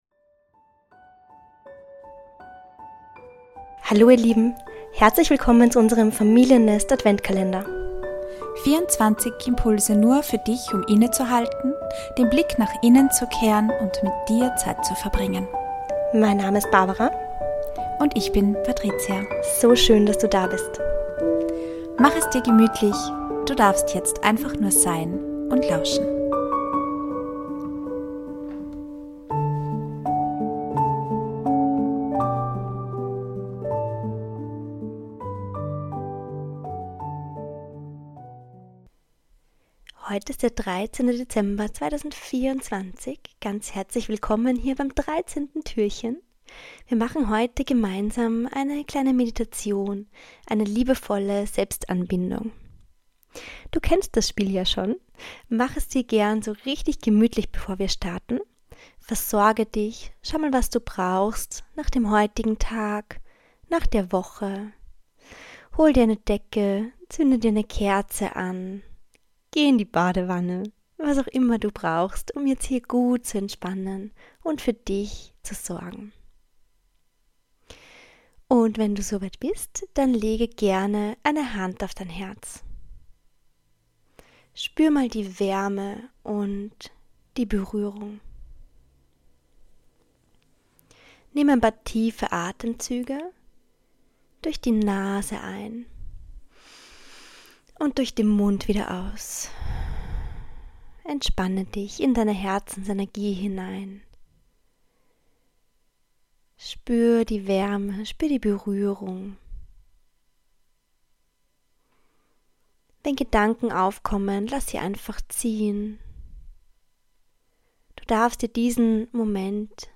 Tür 13 - Willkommen im Familiennest - Adventkalender (Repost): Meditation - Liebevolle Selbstanbindung